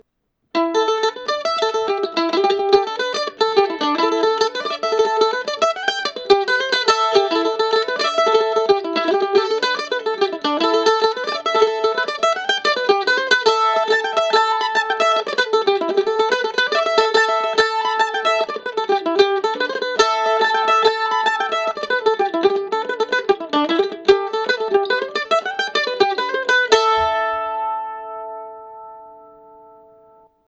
THE MANDOLIN
Here are some sound samples of the “Rhapsody” mandolin recorded by my new friend
I made the sides deeper for this, by 1/2”, it has great volume as a result, a surprise effect was that the sound is bright and not as round as I would have expected.